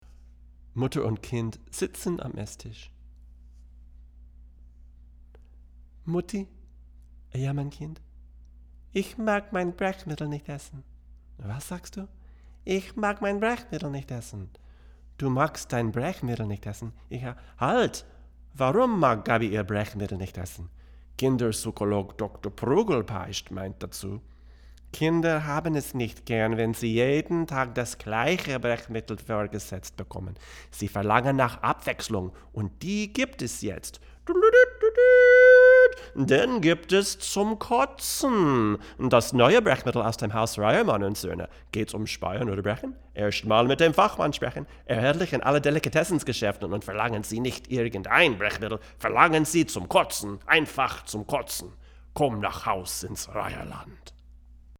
Pearlman TM-LE Stereo Mic Pair
The end result is a mic that has qualities similiar to the U67 from the use of the K67 capsule, and also qualities from the U47 which come from the type of circuit that is used.
Here are some MP3 clips for the TM-LE, in a large room using a Audient Black mic pre and a Sony PCM D1 Flash Recorder (email me if you want any of these files in the WAV format):
VOICEOVER